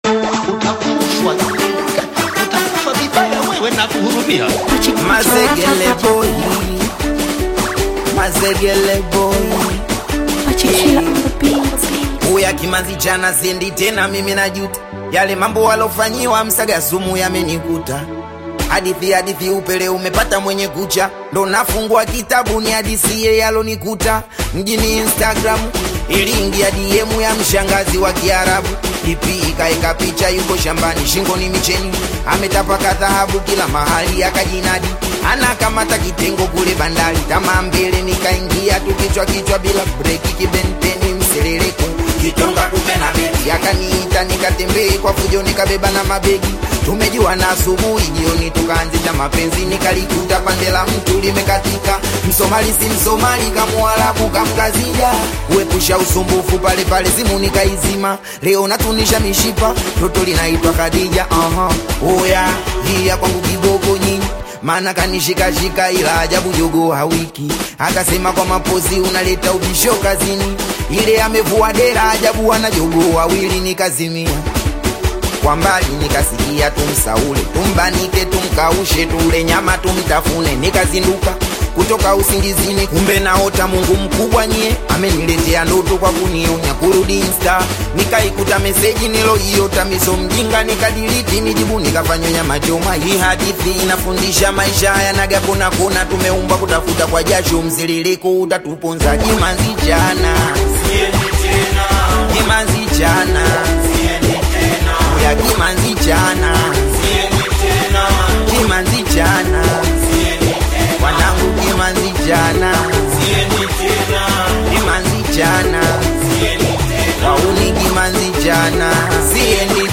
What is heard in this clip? AudioSingeli Genre: Singeli